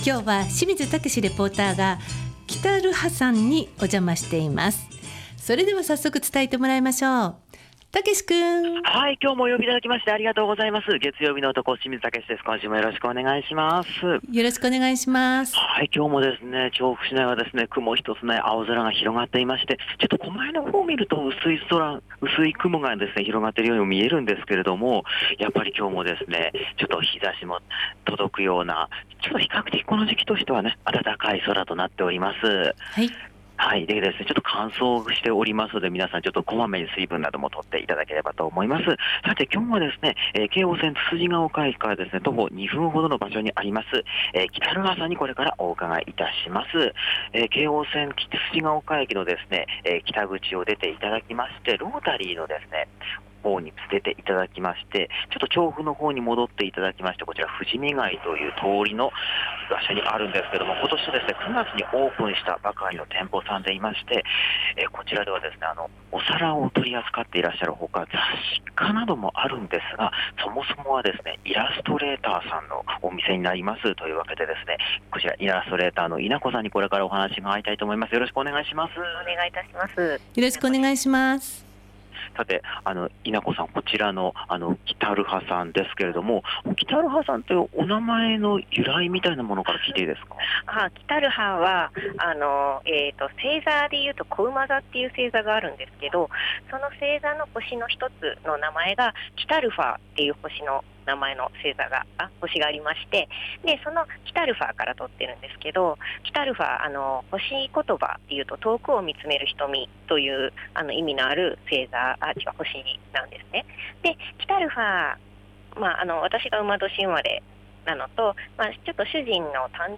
今週も暖かい気候の空の下からお届けした、本日の街角レポートは 9月２日にオープンされた雑貨とギャラリー『きたるは』さんからのレポートでした。